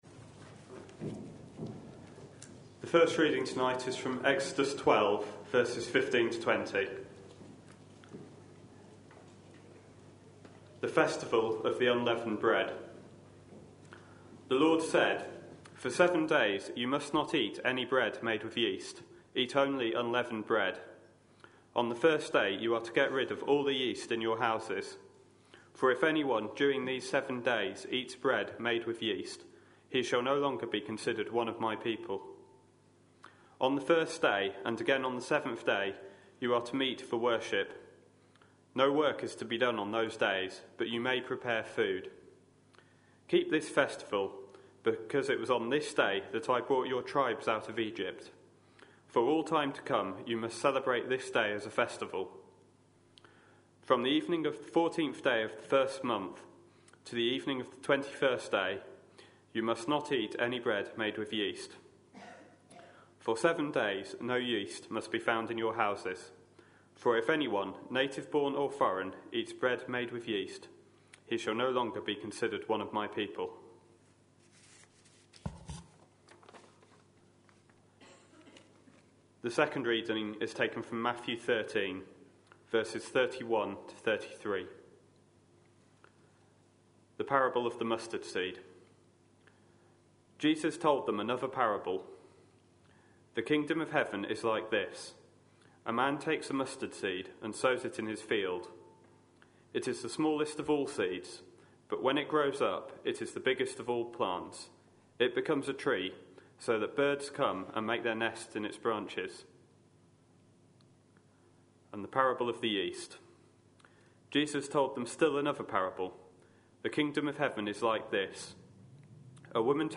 A sermon preached on 2nd February, 2014, as part of our Stories with Intent series.